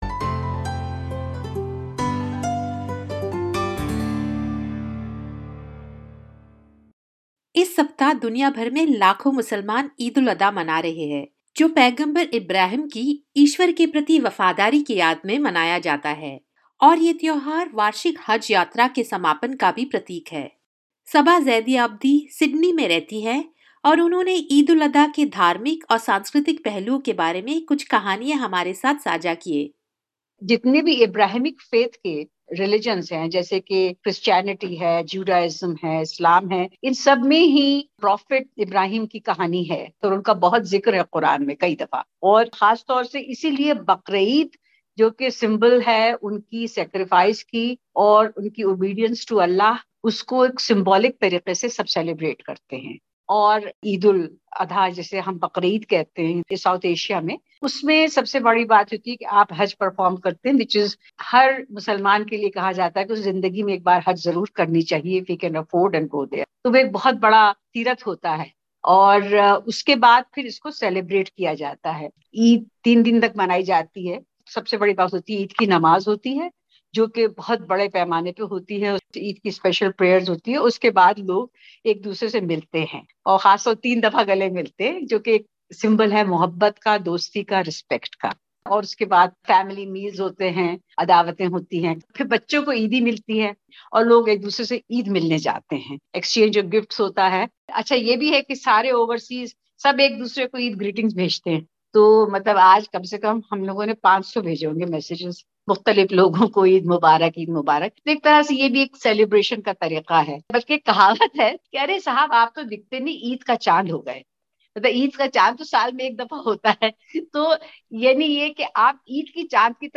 In Australia, Muslim community celebrated Eid this year with a lot of joy and love. In an interview with SBS Hindi, community members spoke about the significance of the day, the traditions surrounding it, and the joy they felt when they got together with family, friends, and the community.